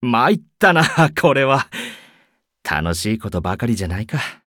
文件 文件历史 文件用途 全域文件用途 Ja_Bhan_fw_02.ogg （Ogg Vorbis声音文件，长度4.5秒，103 kbps，文件大小：56 KB） 源地址:游戏语音 文件历史 点击某个日期/时间查看对应时刻的文件。 日期/时间 缩略图 大小 用户 备注 当前 2018年5月25日 (五) 02:59 4.5秒 （56 KB） 地下城与勇士  （ 留言 | 贡献 ） 分类:巴恩·巴休特 分类:地下城与勇士 源地址:游戏语音 您不可以覆盖此文件。